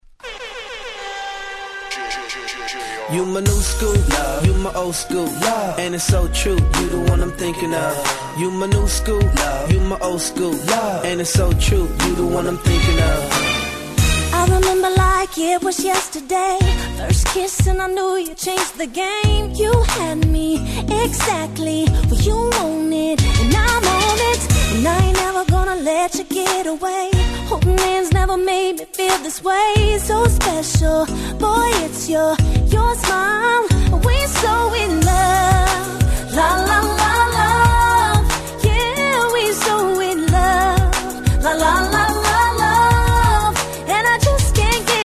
07' Smash Hit R&B♪
キャッチーで人気の1曲♪